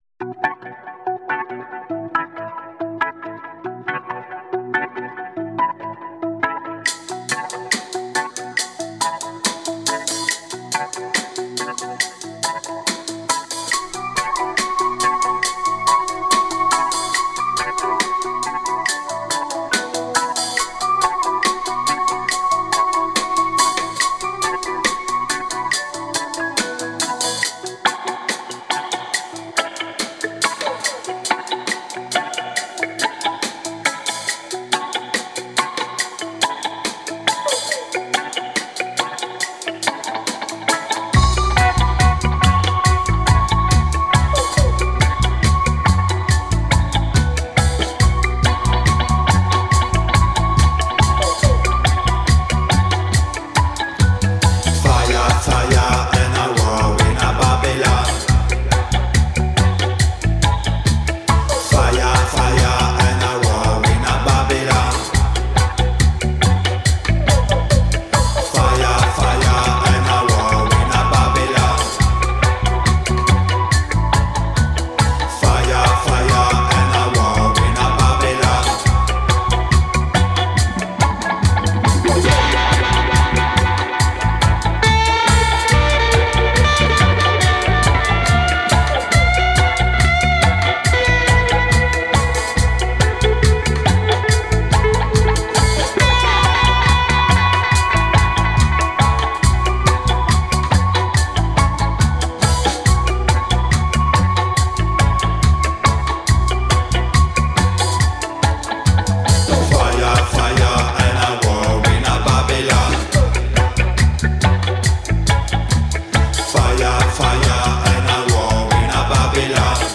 reggae/dub/jungle